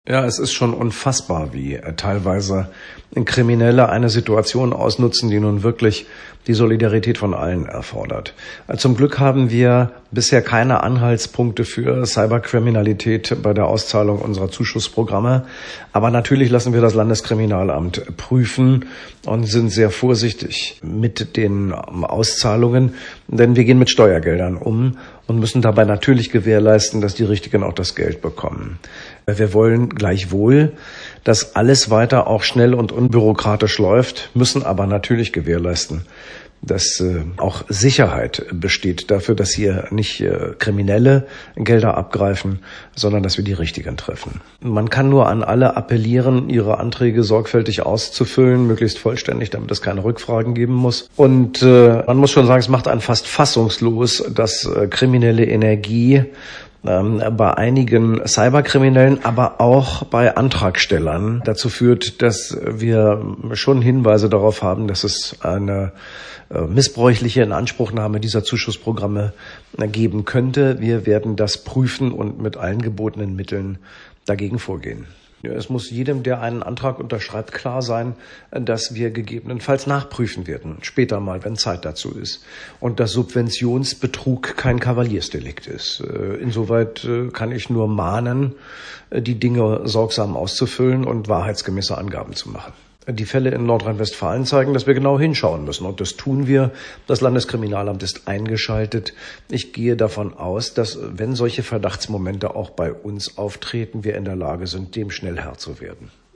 _D0A1873-BearbeitetWeiter sagte Buchholz